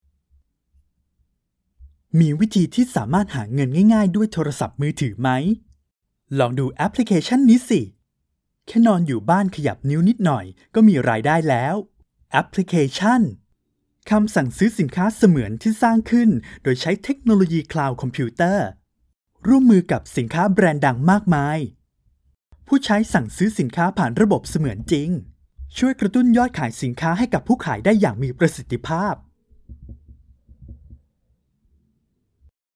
宣传片-年轻活力